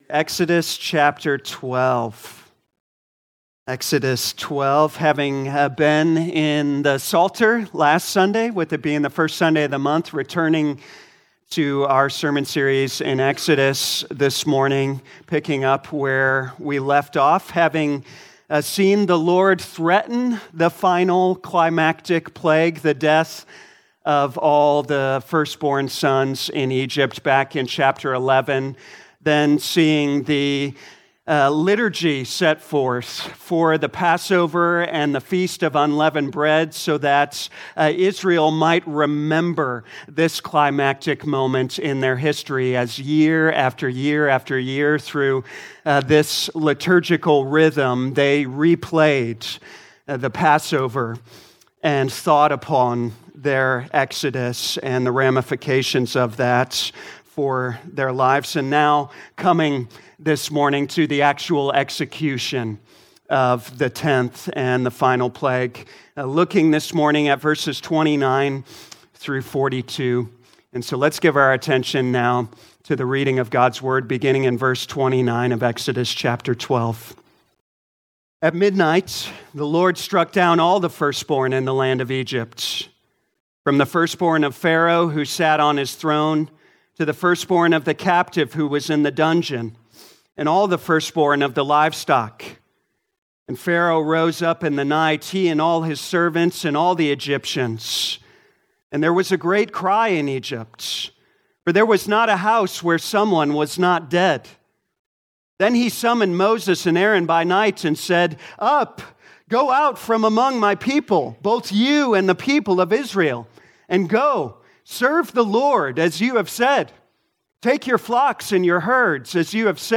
2025 Exodus Morning Service Download